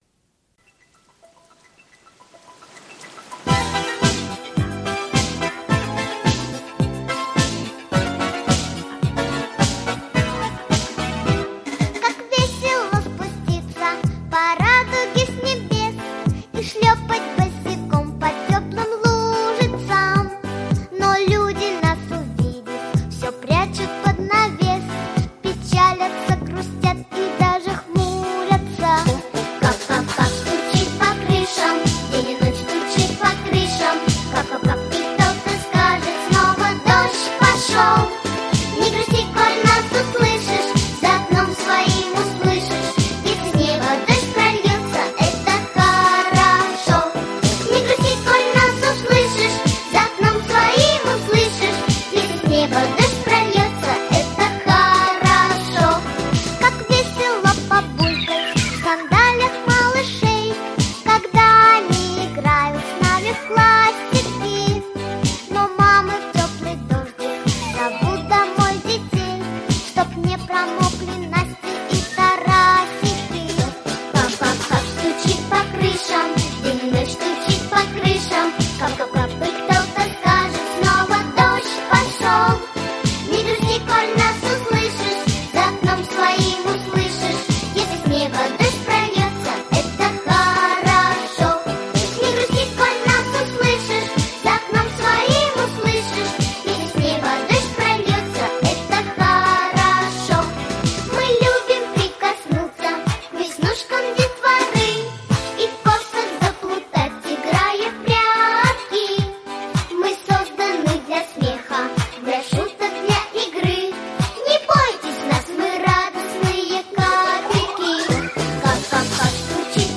Песенки про осень